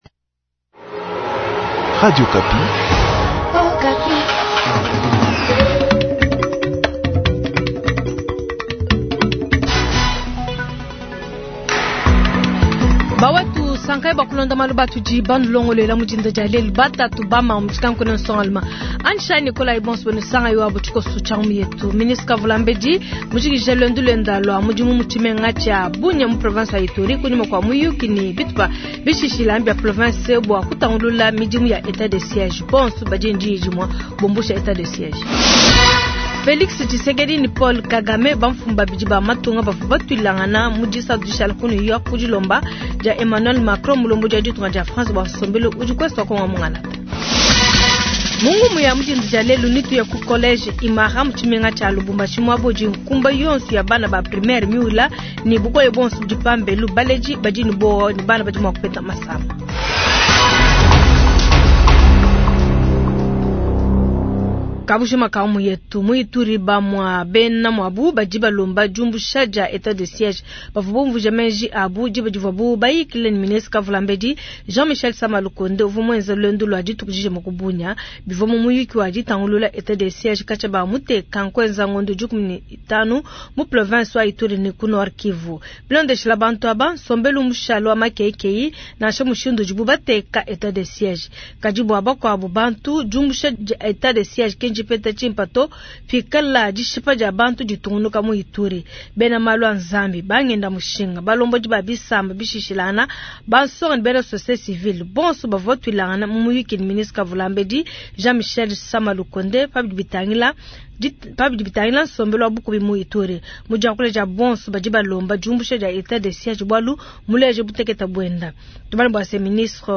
Journal du Vendredi 230922